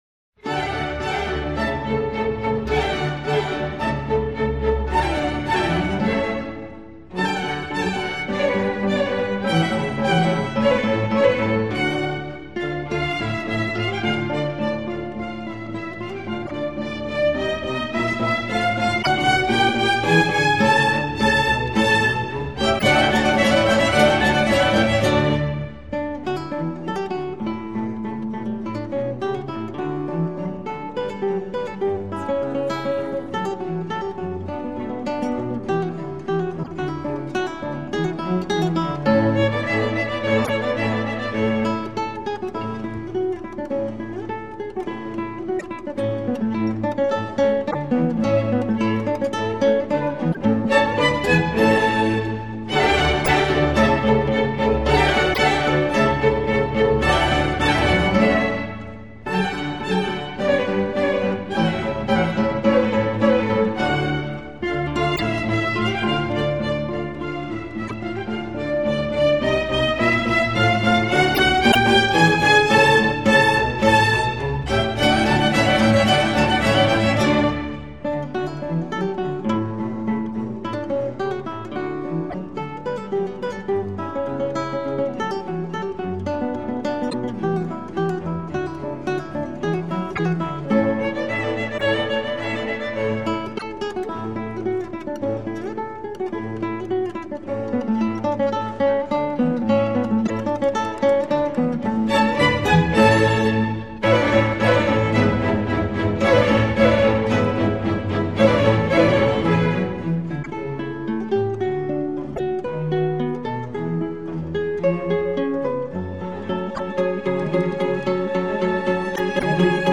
0420_3e_mvt_Allegro_Co_en_re_pour_guitare_et_orchestre.mp3